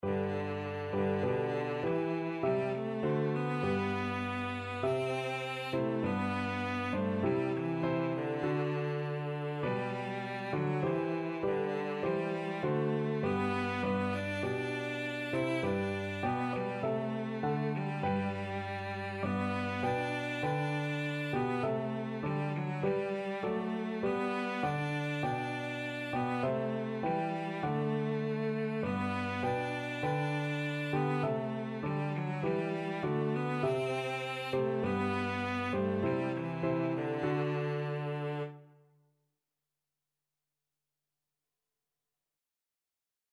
Cello
4/4 (View more 4/4 Music)
G major (Sounding Pitch) (View more G major Music for Cello )
Traditional (View more Traditional Cello Music)
Irish